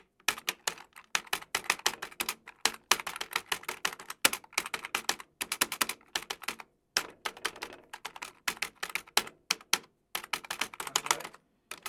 Teclas sueltas de una máquina de escribir electrónica
máquina de escribir
Sonidos: Oficina